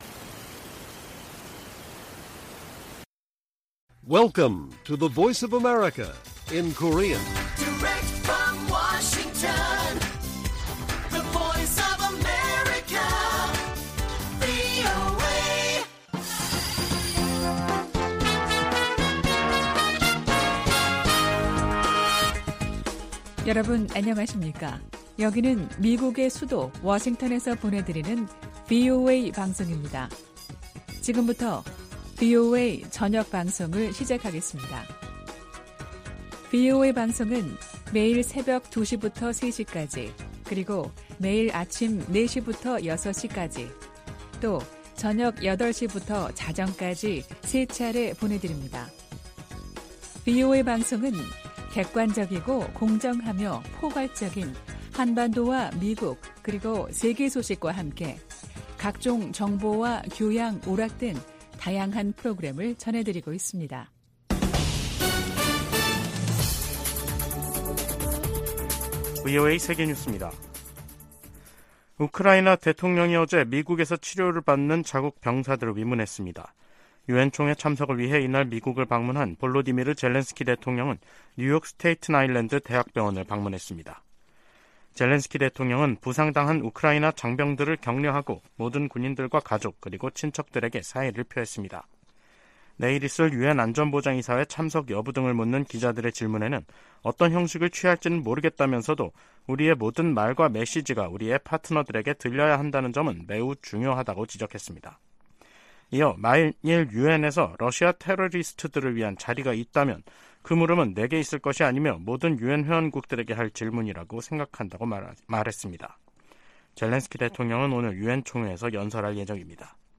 VOA 한국어 간판 뉴스 프로그램 '뉴스 투데이', 2023년 9월 19일 1부 방송입니다. 존 커비 백악관 국가안전보장회의(NSC) 전략소통조정관은 북한과 러시아가 무기거래를 할 경우 유엔 회원국과 대응책을 모색할 것이라고 밝혔습니다. 러시아가 북한 김정은 국무위원장에 무인기를 선물한 데 대해 미국 정부가 제재 부과 의지를 밝혔습니다. 북한과 중국, 러시아가 현재 3각 연대를 형성하고 있는 것은 아니라고 필립 골드버그 주한 미국 대사가 말했습니다.